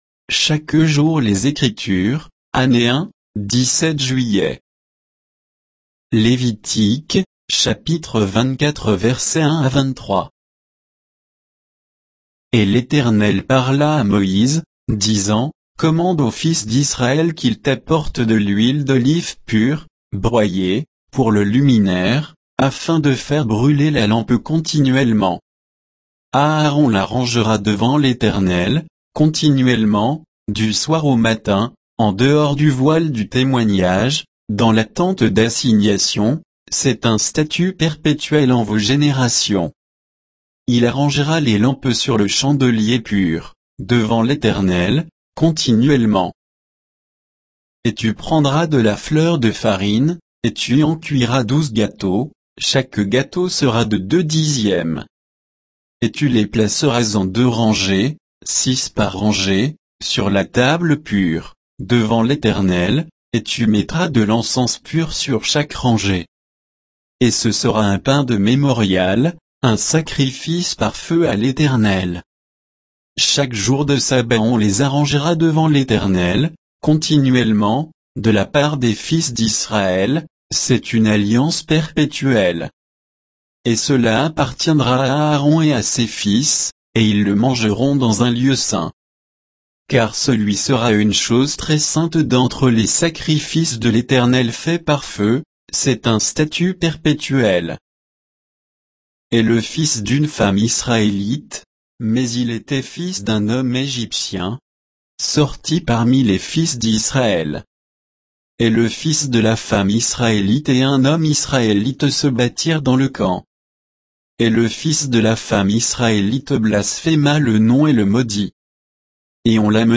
Méditation quoditienne de Chaque jour les Écritures sur Lévitique 24